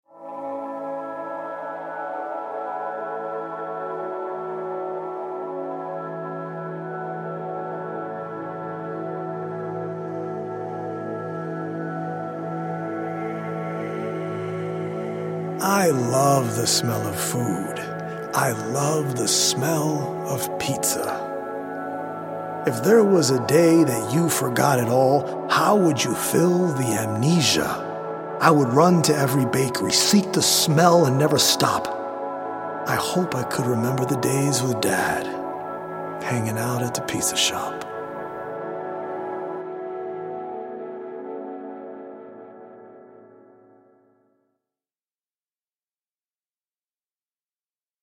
Senses is a healing audio-visual poetic journey through the mind-body and spirit that is based on 100 original poems written/performed by
healing Solfeggio frequency music